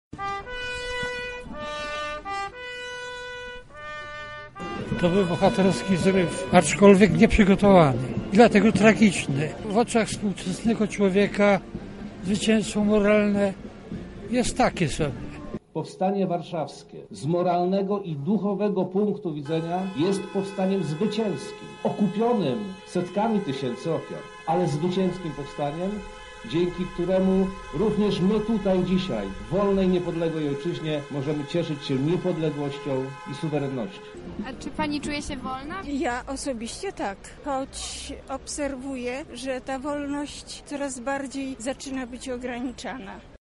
Władze województwa lubelskiego, Urząd Marszałkowski, przedstawiciele Ratusza, harcerze a także żołnierze Armii Krajowej i mieszkańcy naszego miasta oddali cześć bohaterom Powstania Warszawskiego oraz uczestnikom akcji „Burza” przeprowadzonej na Lubelszczyźnie w lipcu 1944 roku. Uroczystości miały miejsce na Placu Litewskim.